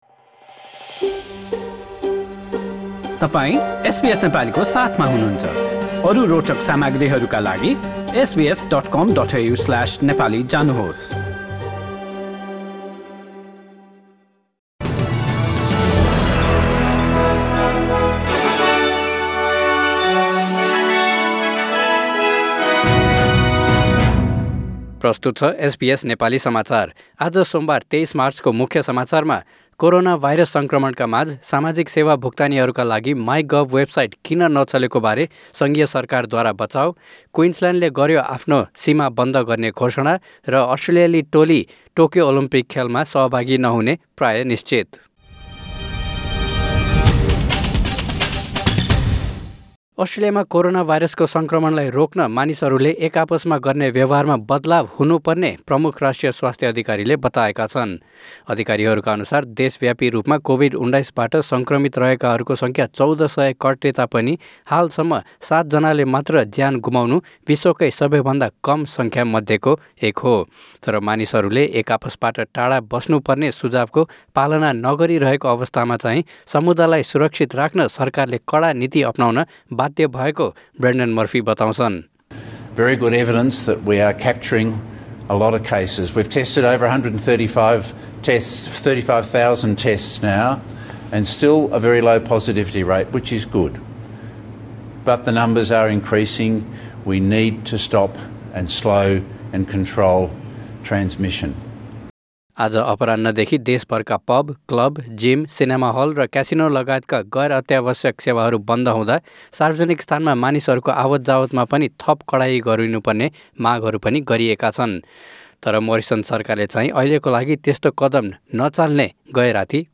Listen to the latest news headlines in Australia from SBS Nepali radio